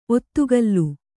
♪ ottugallu